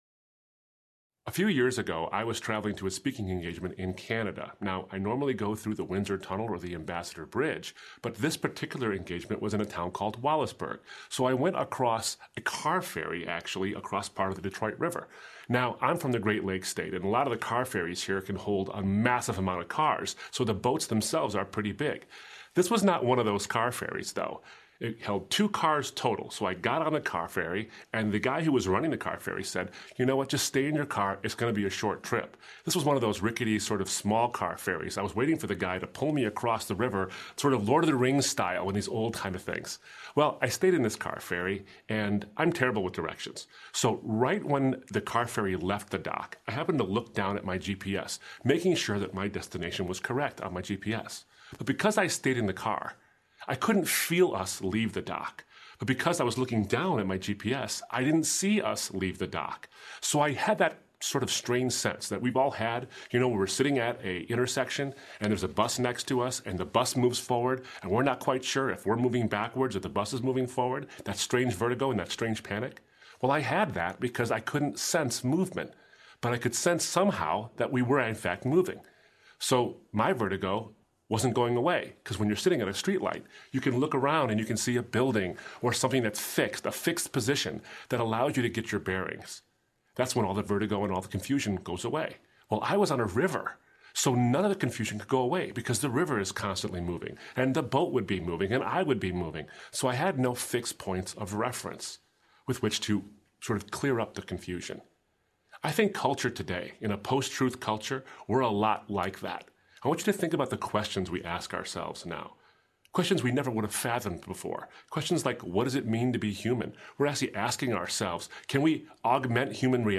Unlike a traditional audiobook’s direct narration of a book’s text, Saving Truth: Audio Lectures includes high-quality live recordings of college-level lectures that cover the important points from each subject as well as relevant material from other sources.